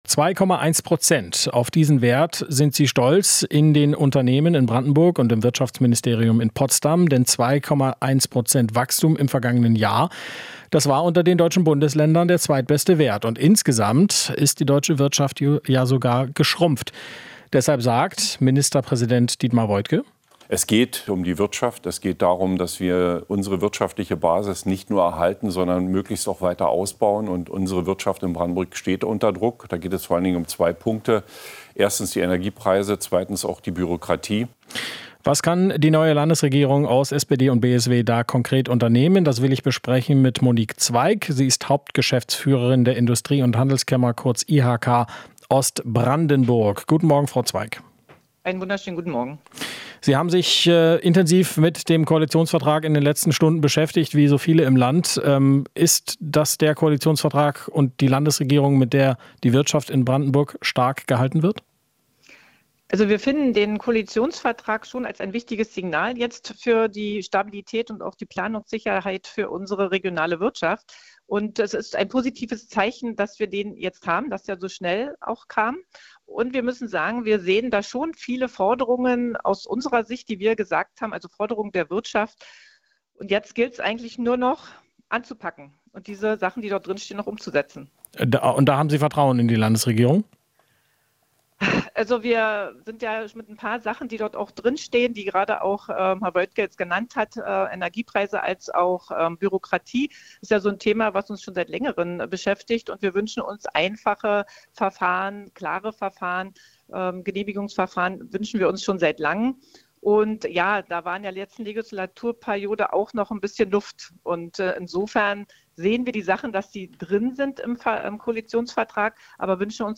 Interview - IHK lobt Koalitionsvertrag zwischen SPD und BSW